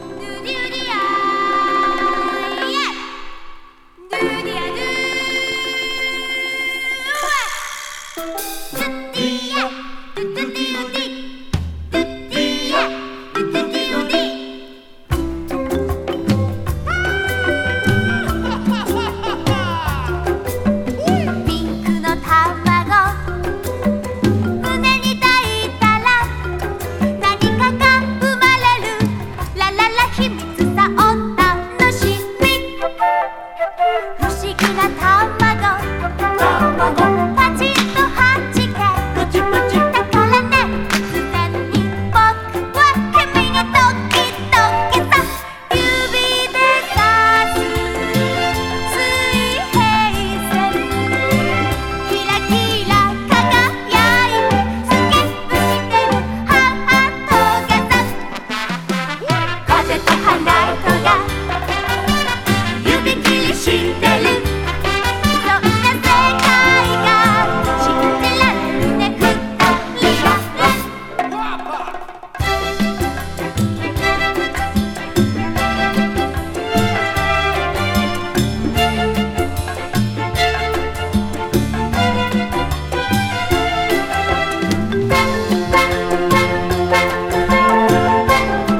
オープニングテーマ